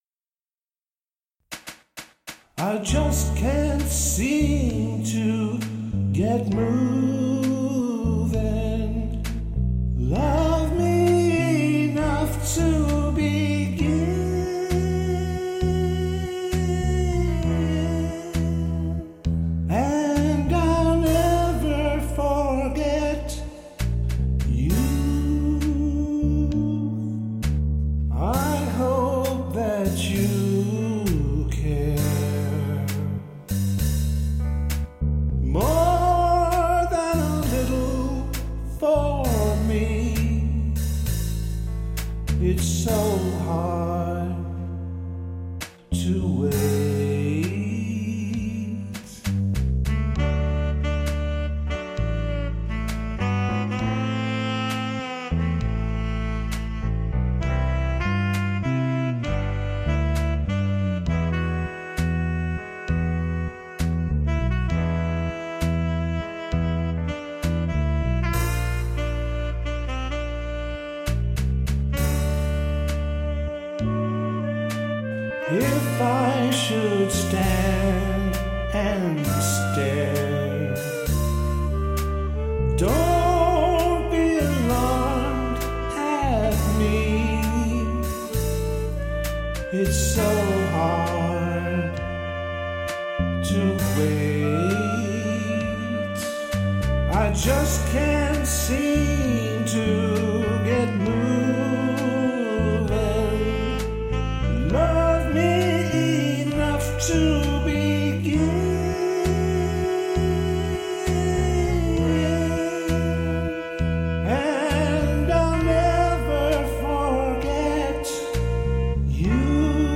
- Classic Rock Covers -
Volume 2 - Classic Country / Folk Rock